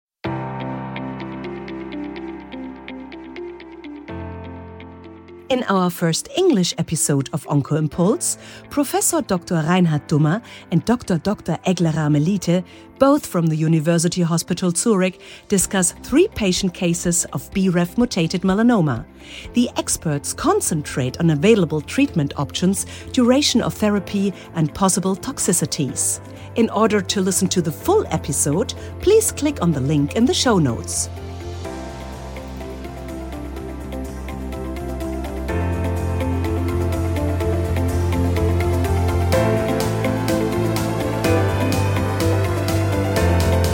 Talk
The two experts discuss the therapy